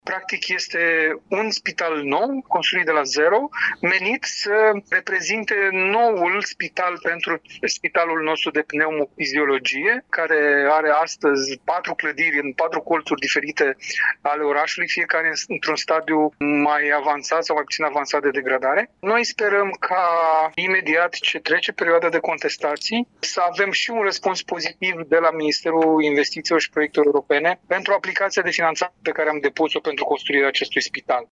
Vice-președintele Consiliului Județean Iași, Marius Dangă, a declarat că numărul de paturi va rămâne același – 350, dar activitățile nu se vor mai desășura în patru corpuri situate în diferite cartiere ale Iașiului.